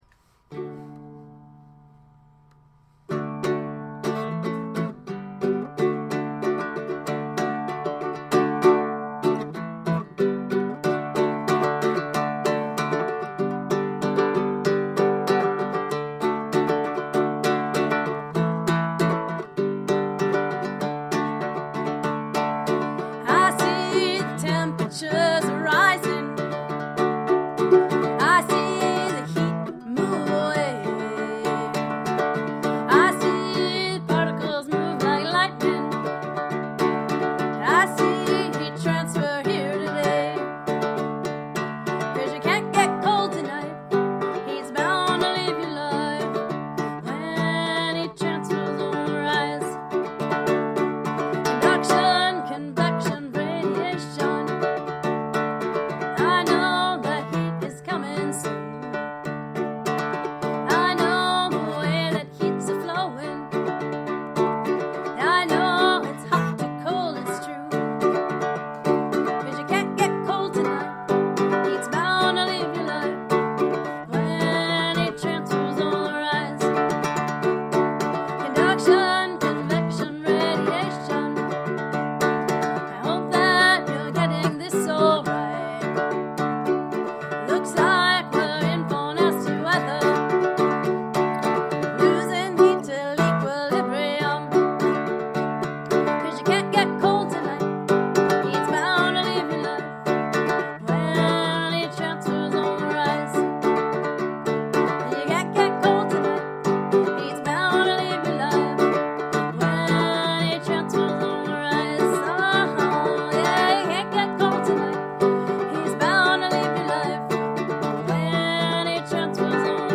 singing and playing the ukelele